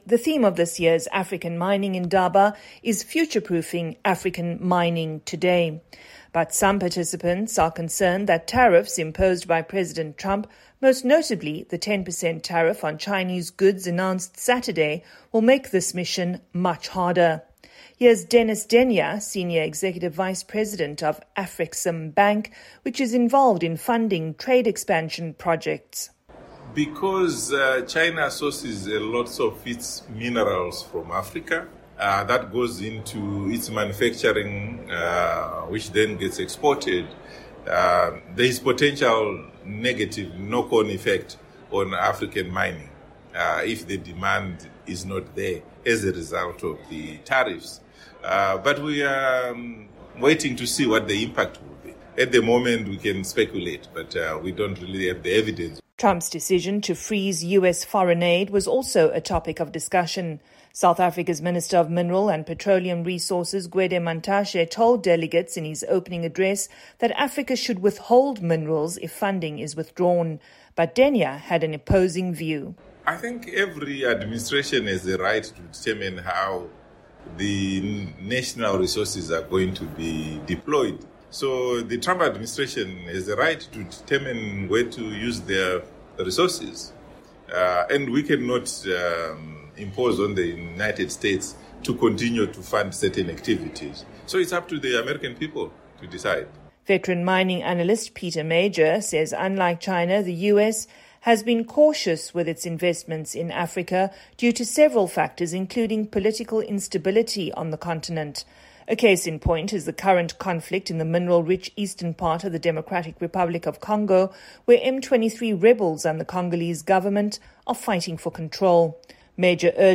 The 31st African Mining Indaba, or conference, is underway in Cape Town, South Africa with over 115 countries represented. At the forefront of many discussions are the plans of U.S. President Donald Trump, and the effect that U.S. tariffs and the freeze on foreign aid might have on Africa’s mining industries.